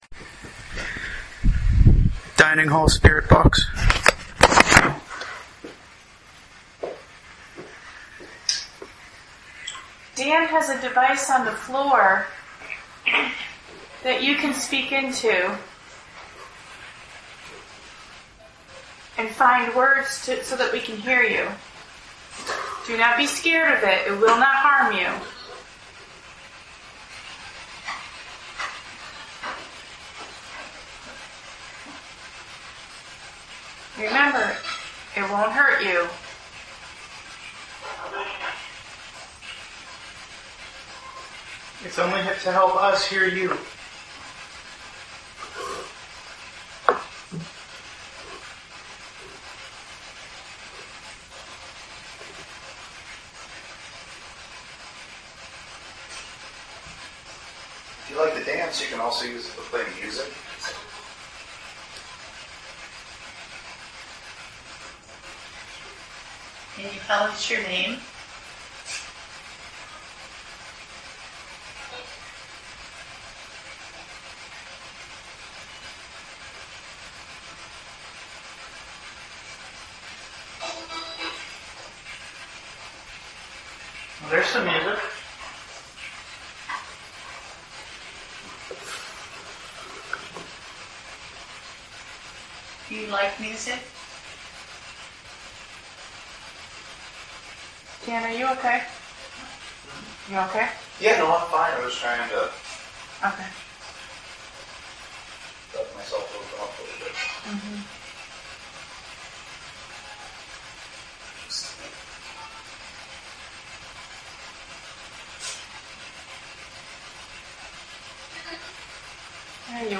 Recorder 2 – Spirit Box:
• At :27 we captured “Hello” followed by “Help me” at :37.